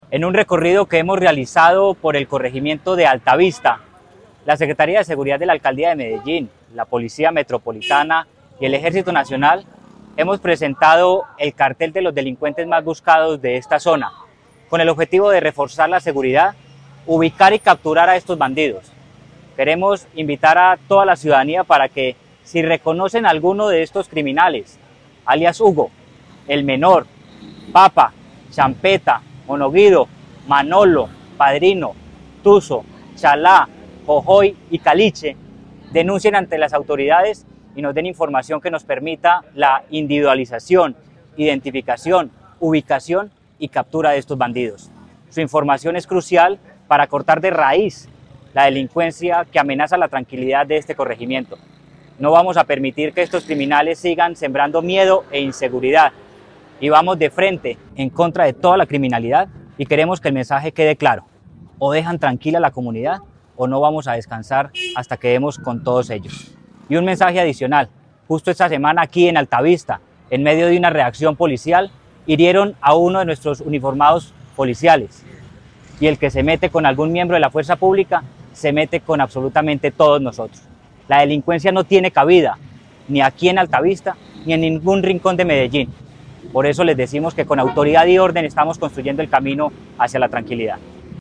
Palabras de Manuel Villa Mejía, secretario de Seguridad y Convivencia